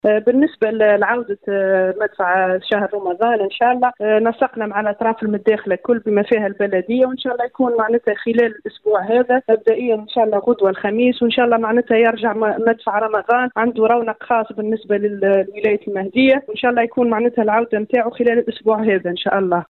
أكدت معتمدة المهدية، ضحى بن عبد الله، في تصريح ل “ام اف ام”، عودة مدفع رمضان بداية من يوم الغد، وذلك بعد التنسيق مع الاطراف المتداخلة من بينها بلدية الجهة.